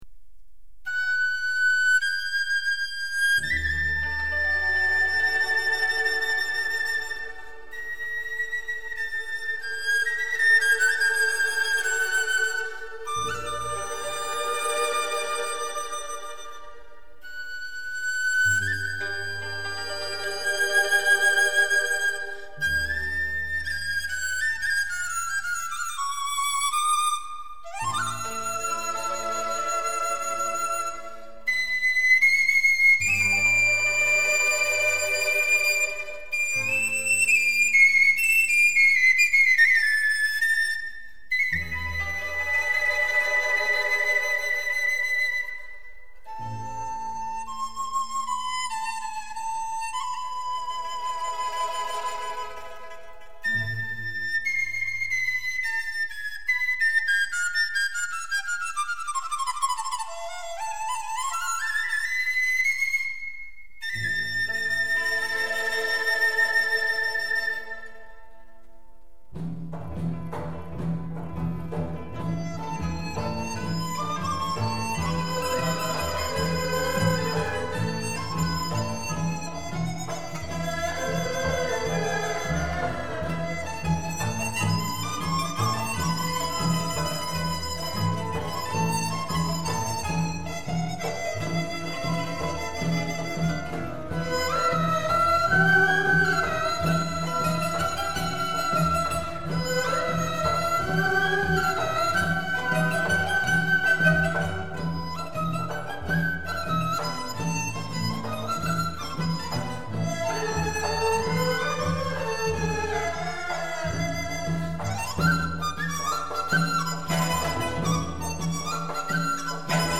(民族管弦乐合奏) 坠胡